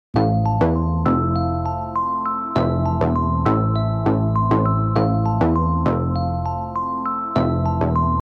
Хип-хоп
Rap